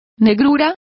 Complete with pronunciation of the translation of blackness.